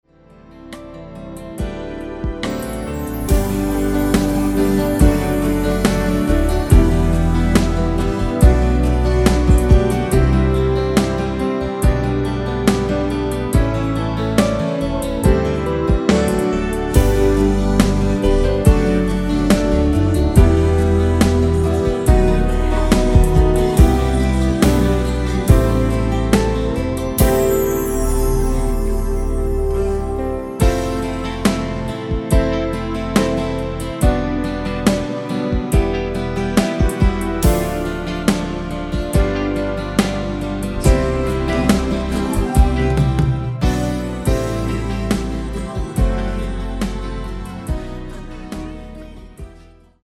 ◈ 곡명 옆 (-1)은 반음 내림, (+1)은 반음 올림 입니다.
앞부분30초, 뒷부분30초씩 편집해서 올려 드리고 있습니다.
위처럼 미리듣기를 만들어서 그렇습니다.